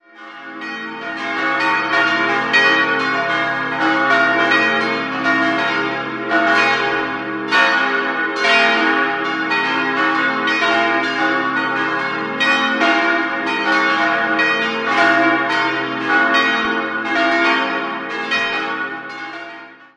5-stimmiges Geläute: des'-f'-as'-b'-des''(-) Die Glocken 1, 2 und 4 wurden 1950 von Karl Czudnochowsky in Erding gegossen, die beiden anderen 1965 von Georg Hofweber in Regensburg.